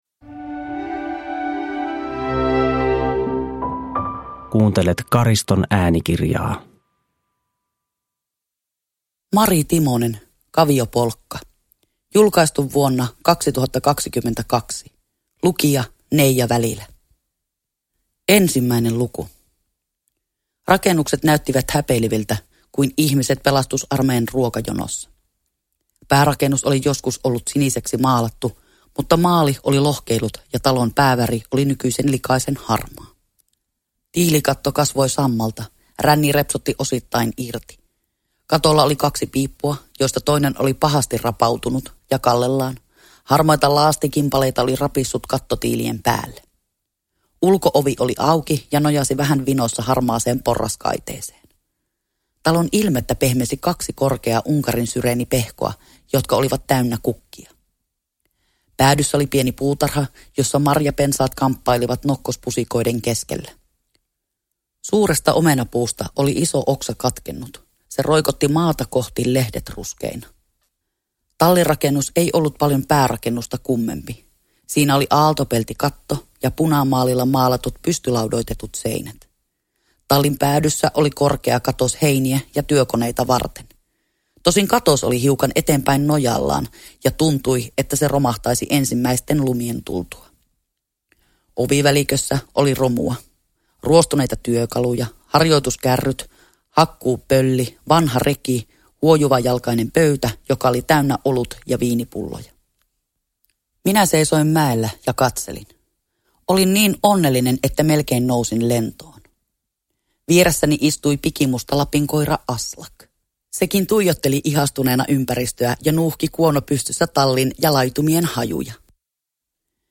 Kaviopolkka – Ljudbok – Laddas ner